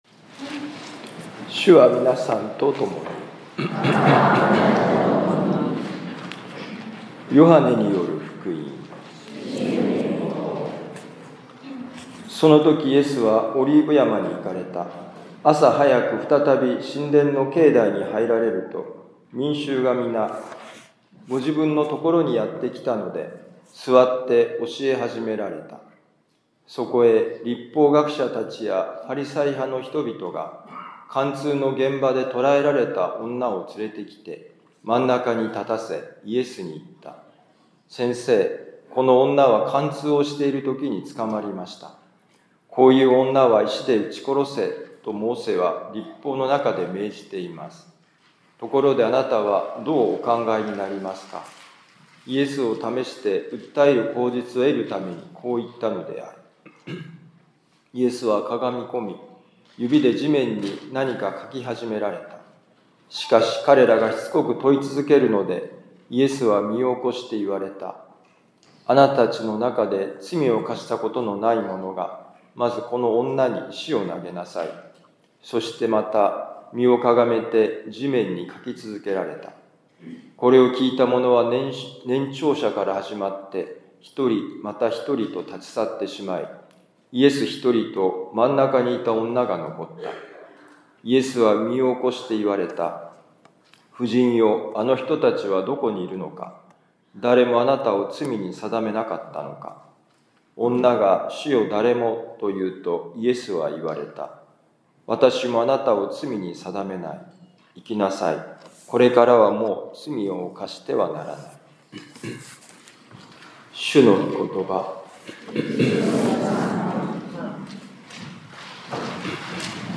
【ミサ説教】
ヨハネ福音書8章1-11節「さらに次の一歩を踏み出していこう」2025年4月6日四旬節第5主日ミサ六甲カトリック教会